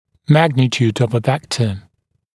[‘mægnɪt(j)uːd əv ə ‘vektə][‘мэгнит(й)у:д ов э ‘вэктэ]величина вектора